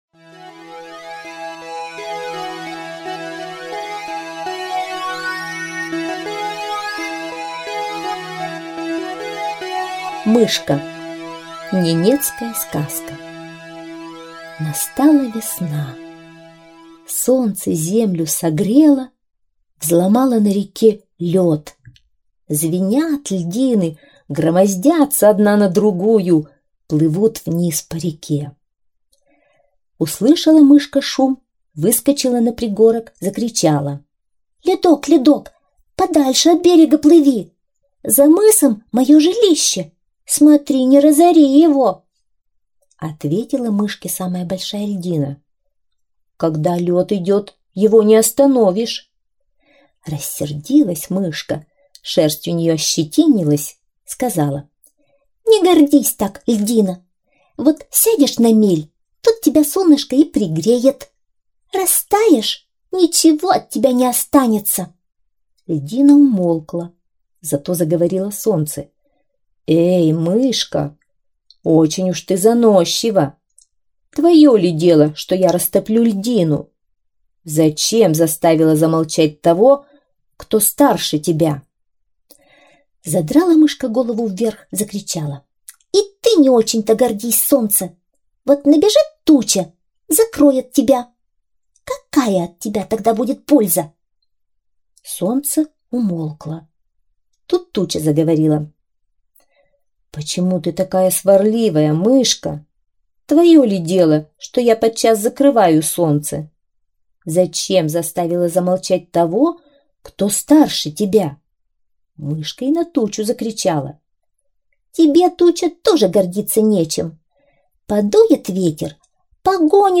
Мышка – ненецкая аудиосказка